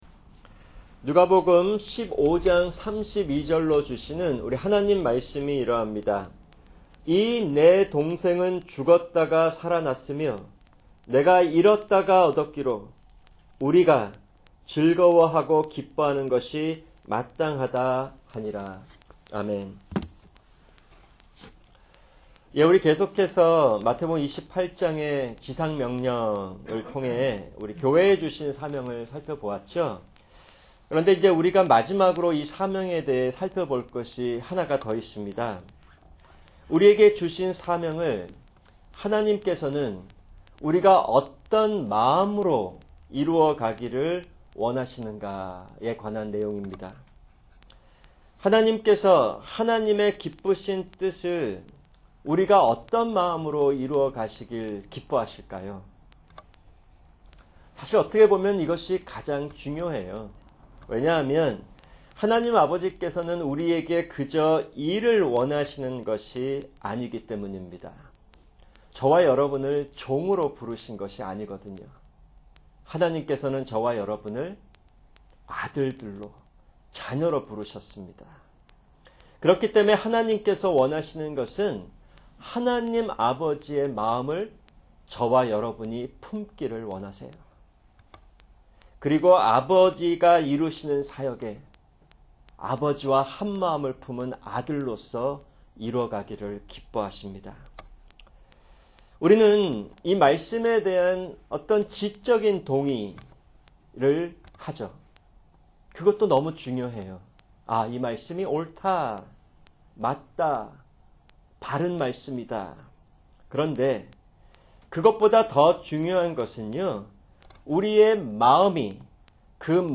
[주일 설교] 누가복음 15:32